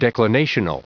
Prononciation du mot declinational en anglais (fichier audio)
declinational.wav